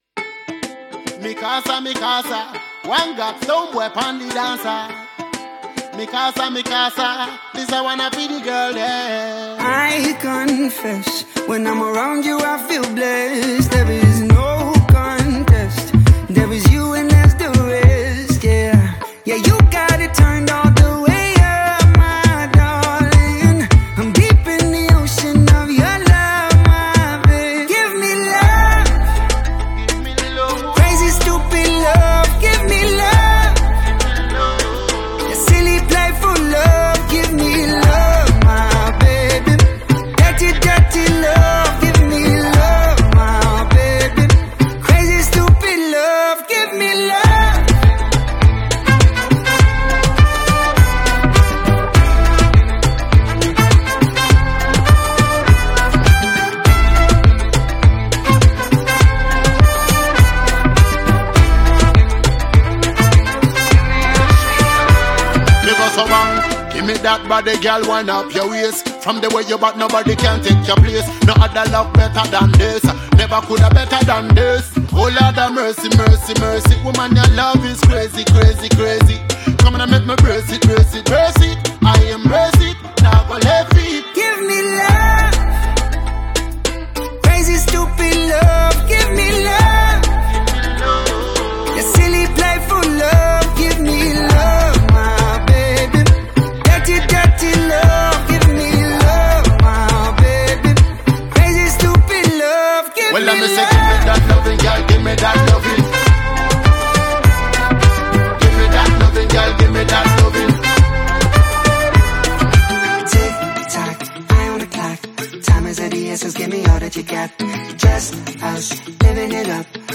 a sensational South Music trio